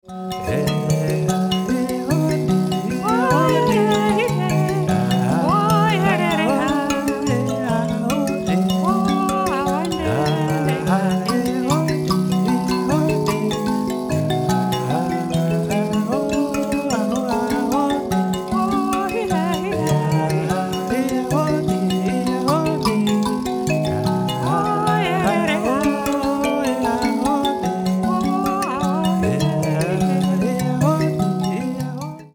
it was recorded in an incredible recording studio
B pitch nyamaropa tuning